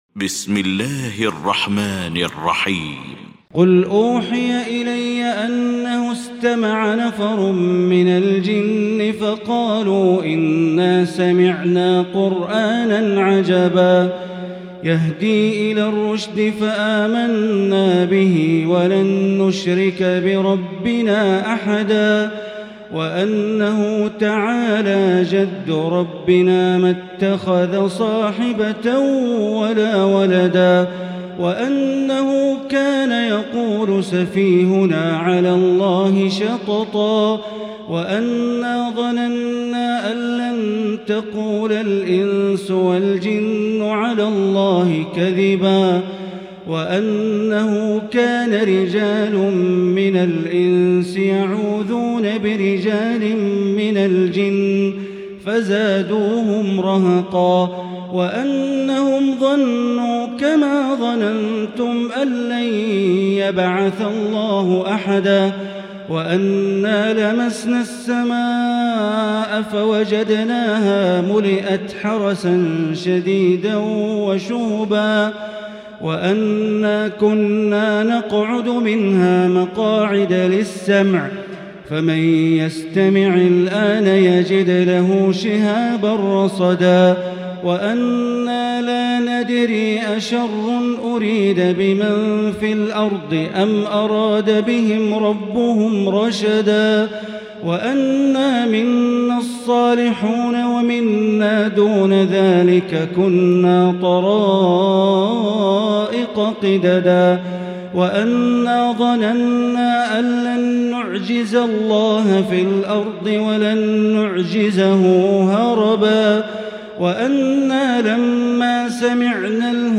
المكان: المسجد الحرام الشيخ: معالي الشيخ أ.د. بندر بليلة معالي الشيخ أ.د. بندر بليلة الجن